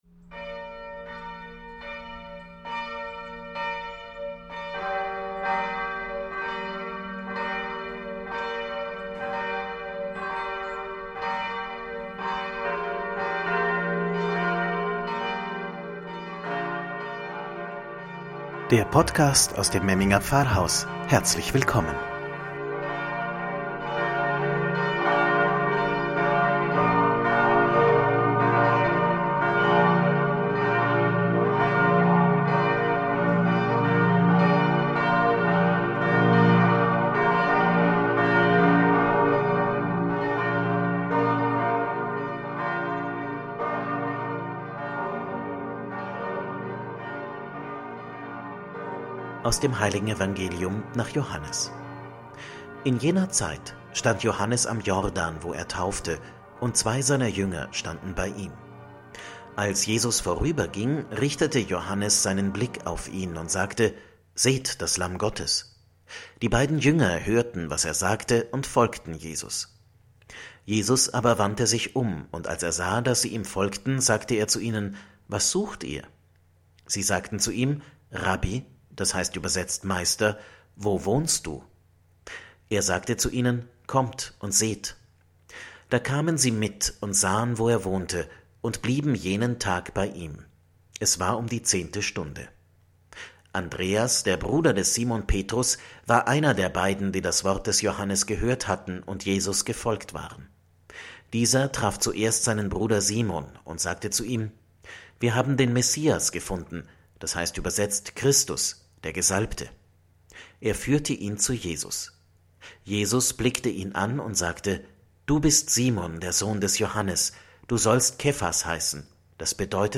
„Wort zum Sonntag“ aus dem Memminger Pfarrhaus – Zweiter Sonntag im Jahreskreis 2021